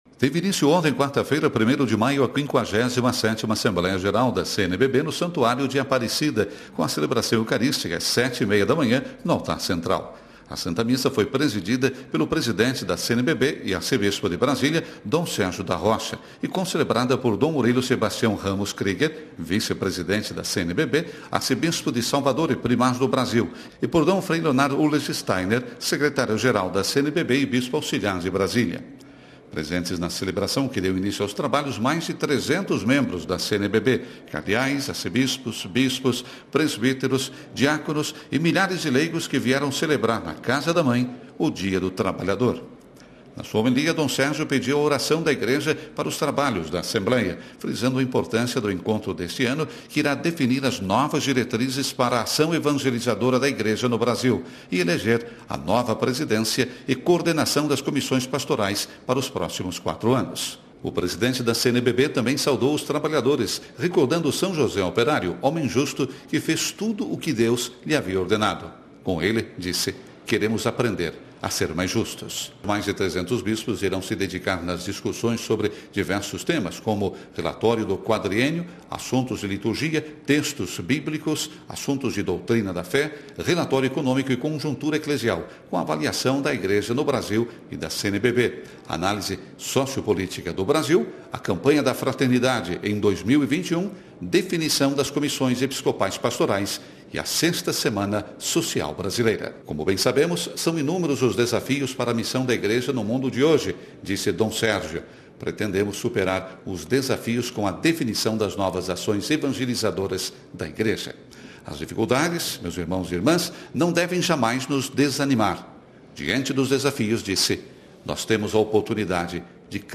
Nós conversamos com Dom Edmilson Amador Caetano Ocist, bispo de Guarulhos, SP.